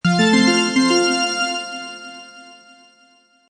musik sukses.wav